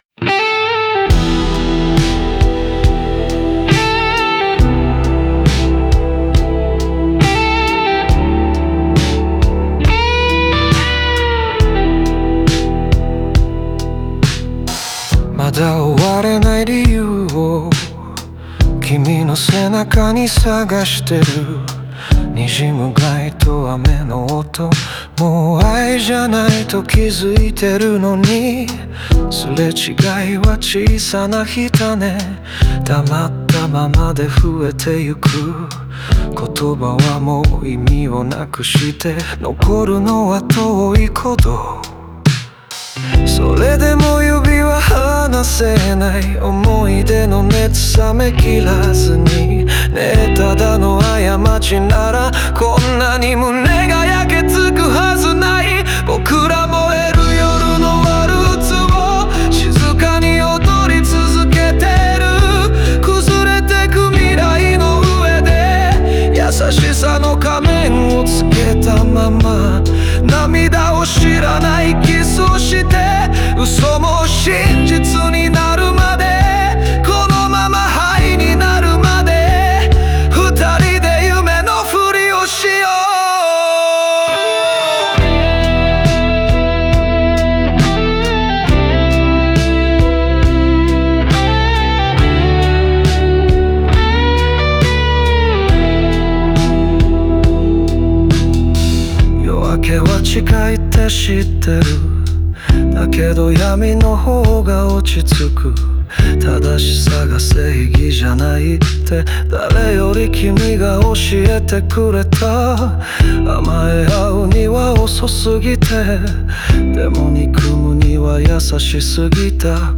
この曲は、終わりを悟りながらも別れきれない男女の関係を描いたバラードである。